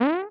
bounce.ogg